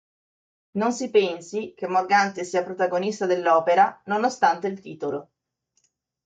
Read more Pron Conj Det Conj Frequency A1 Hyphenated as ché Pronounced as (IPA) /ˈke/ Etymology From Latin quid (but also usurping some roles of Latin quod), from Proto-Indo-European *kʷid, compare *kʷís.